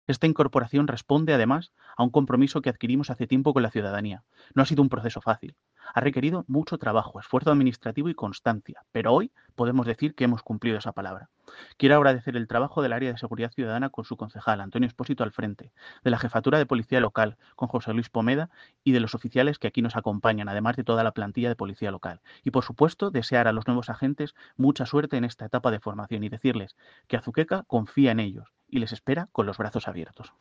Declaraciones del alcalde sobre el proceso y agradecimiento